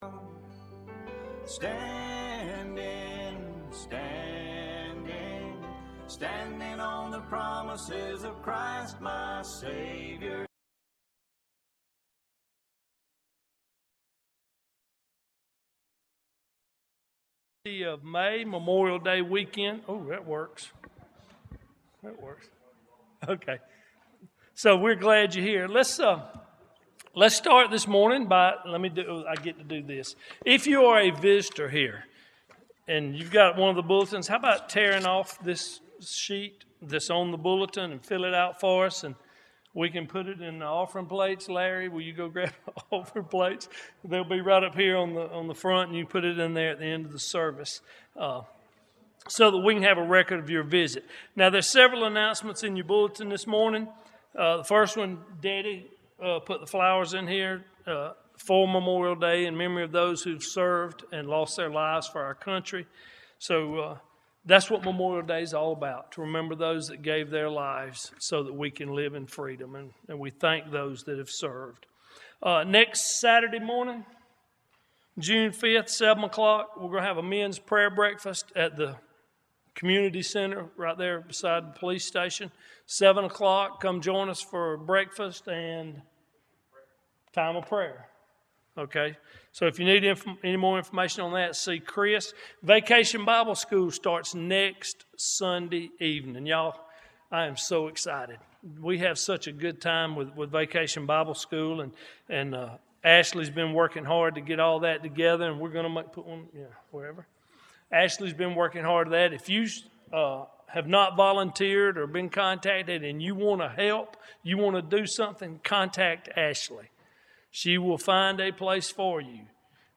Arlington Baptist Church Sermons